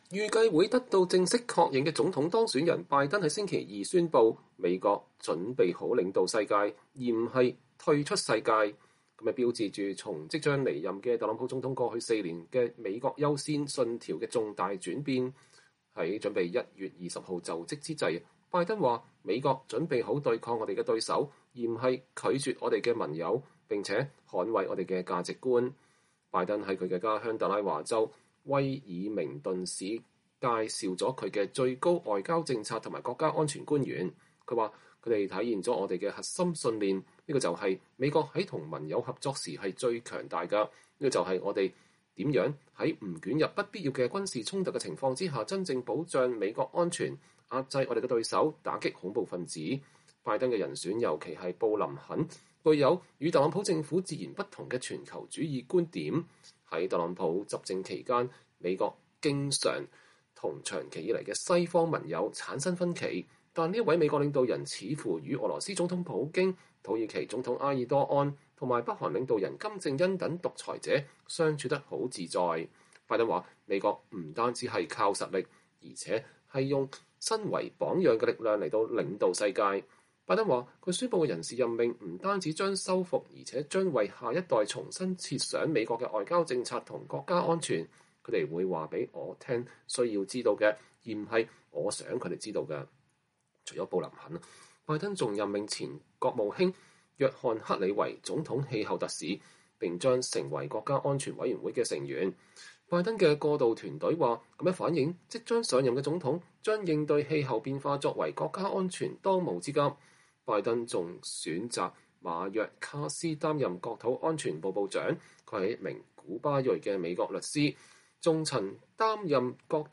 拜登和哈里斯在特拉華州威爾明頓介紹他們的國家安全和外交政策關鍵職位的人事任命。（2020年11月24日）